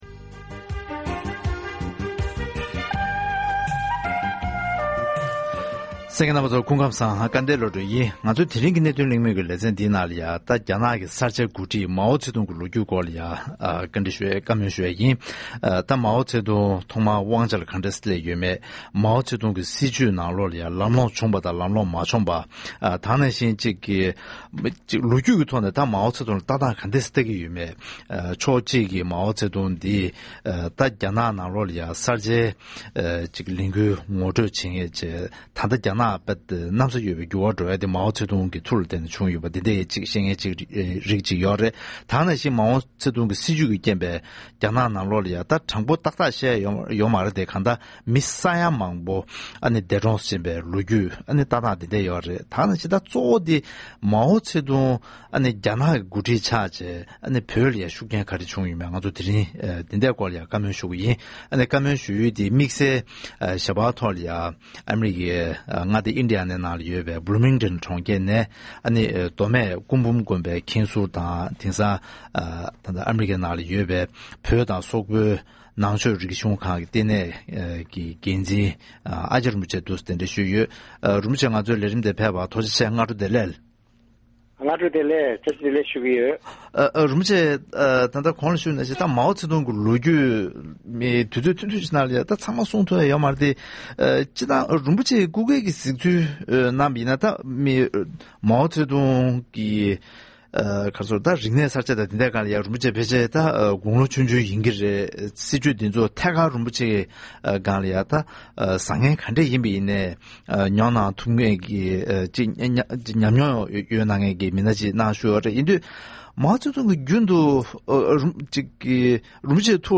རྒྱ་ནག་གི་གསར་བརྗེའི་འགོ་ཁྲིད་མའུ་ཙེ་ཏུང་གི་སྐོར་འབྲེལ་ཡོད་དང་གླེང་མོལ་ཞུས་པ།